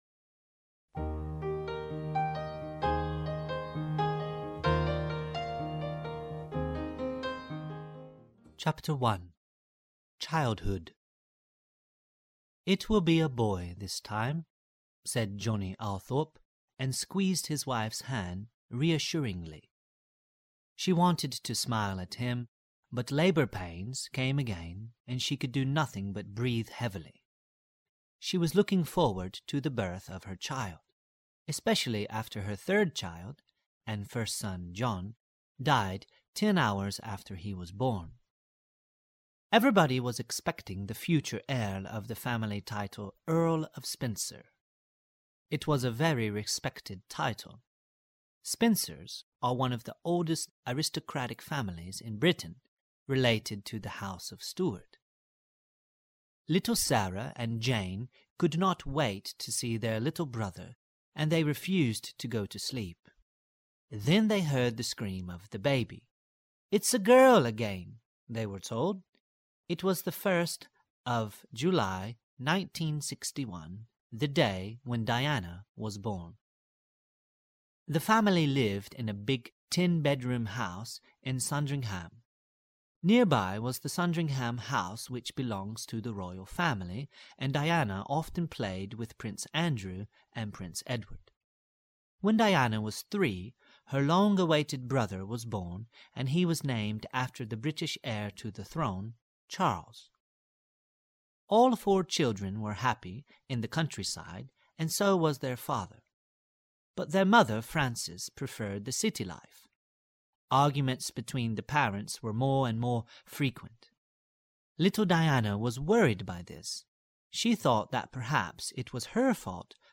Audio kniha
Ukázka z knihy
Text vypráví rodilý mluvčí.